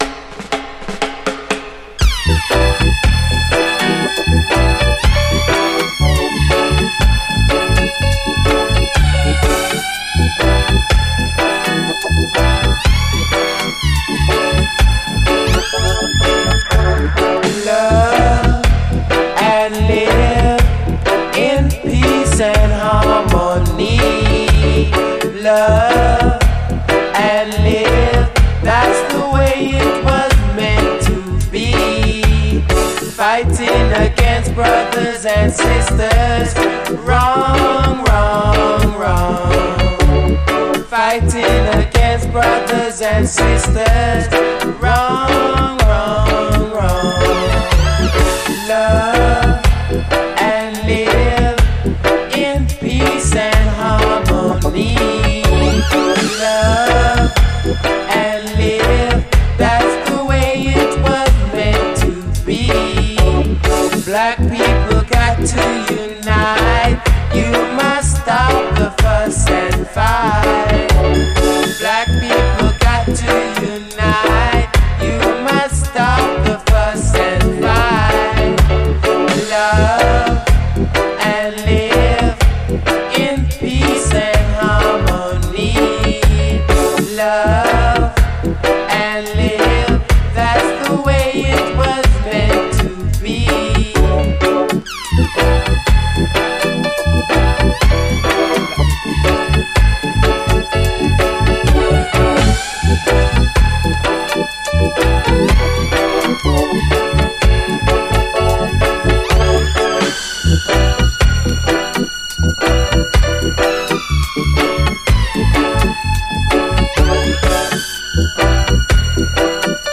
REGGAE
ピュンピュン系シンセが非常に鮮やかな隠れた最高UKラヴァーズ！
B面VG+時々小プチノイズある程度でプレイOK、A面キズによる周回プチノイズありVG/
後半のダブでもシンセが突き抜けます。